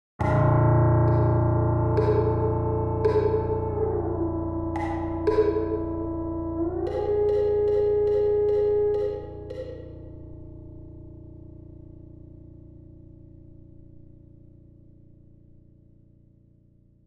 - percussive: This will be simmilar to wooden blocks, marimba-like sounds and possibly membraphone.
melodic: Looking for a sinusodial sound with a minimum (but some) partials.
- Low frequency, sustained sounds: To be used as a pedal, just to fill space when needed.